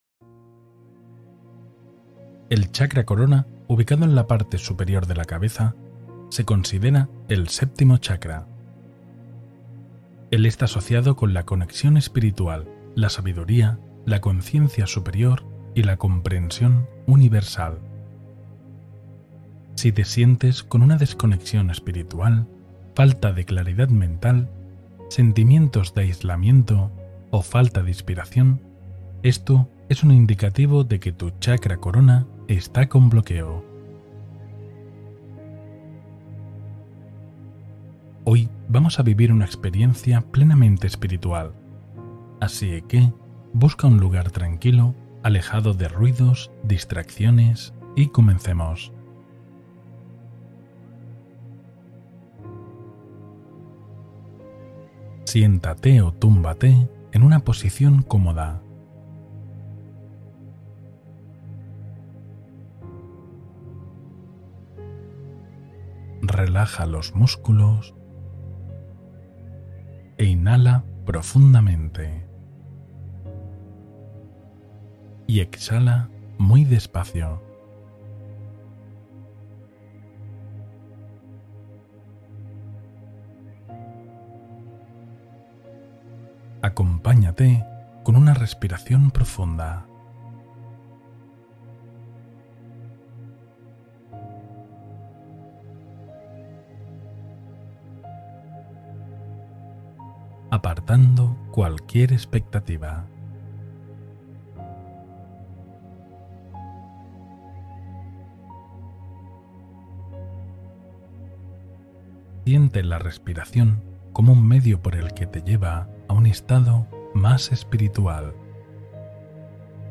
Elevación Energética Consciente: Meditación de Integración Superior